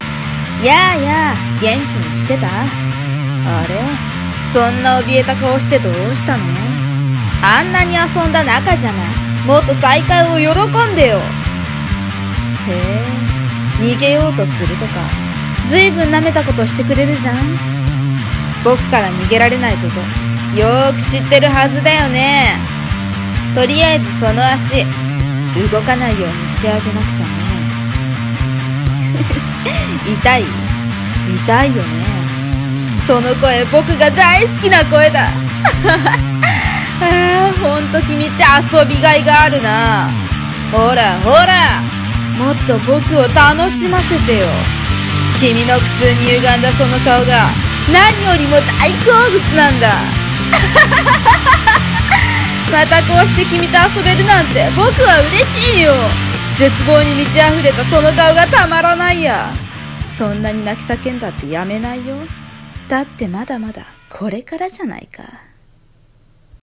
【声劇 台本】また僕(悪役)と遊ぼうよ